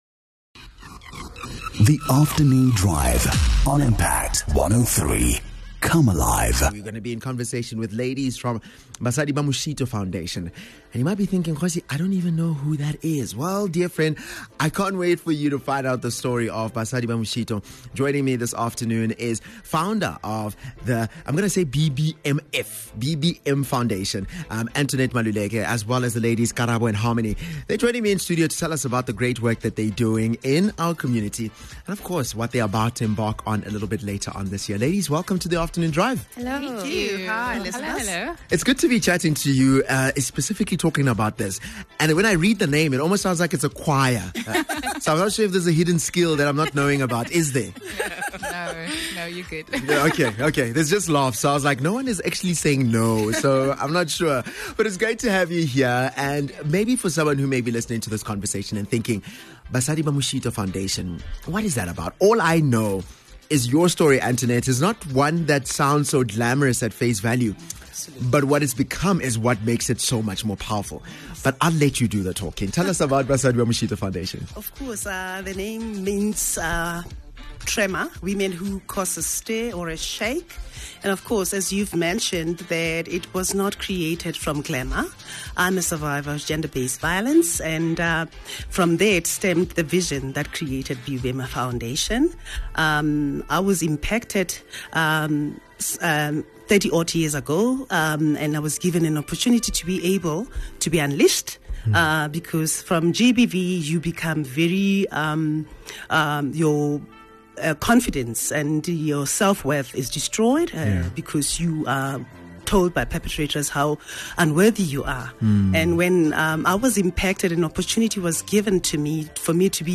Interviews Basadi Ba Moshito Foundation